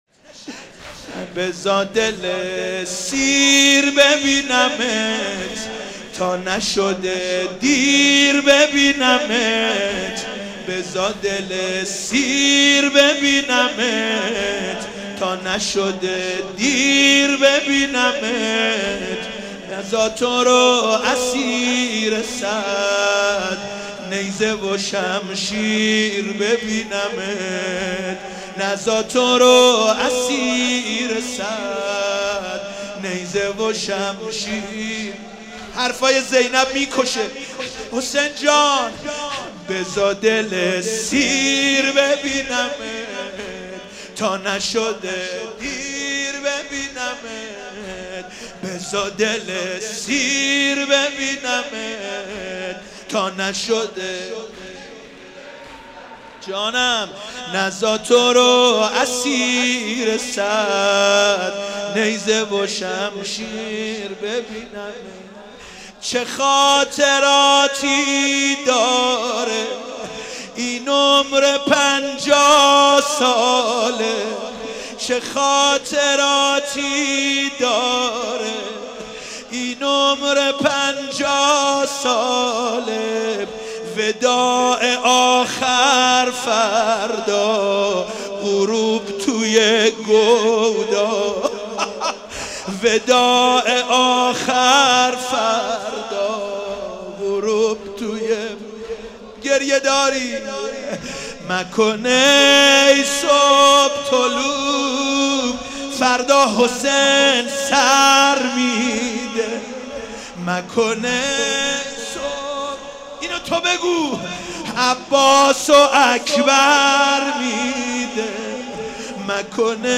شب عاشورا محرم97 - مسجد امیر - زمینه - بزار دل سیر ببینمت